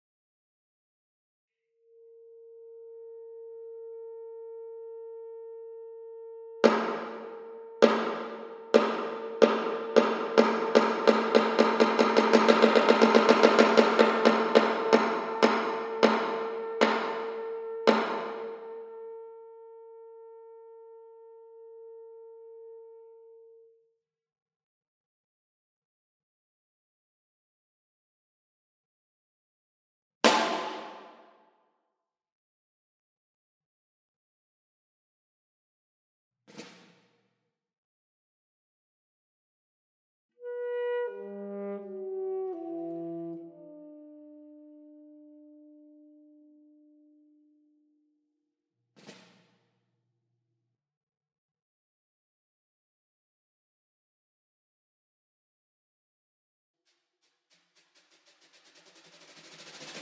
Saxophone, Percussion